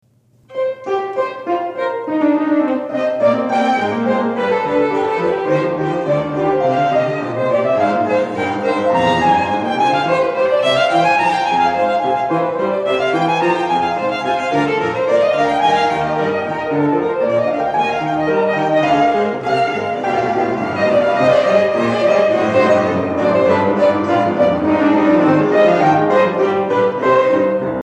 Allegro
Adagio